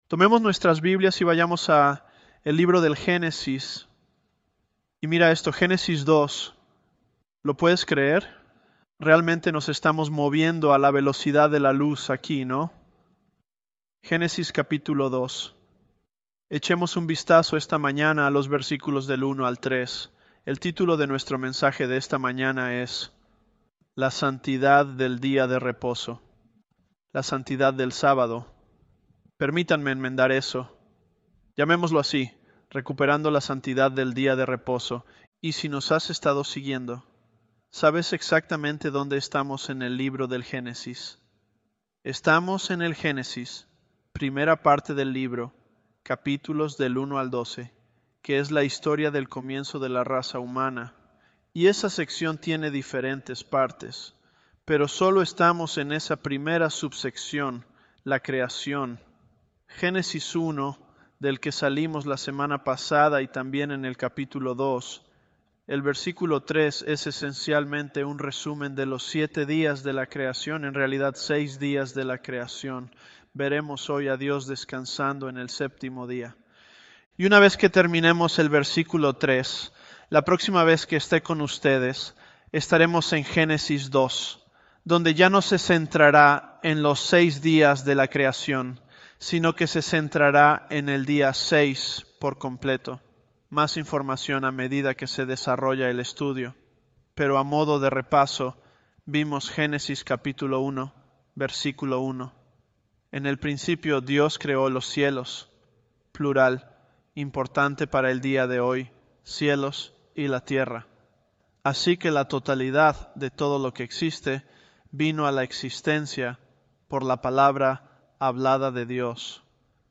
ElevenLabs_Genesis-Spanish007.mp3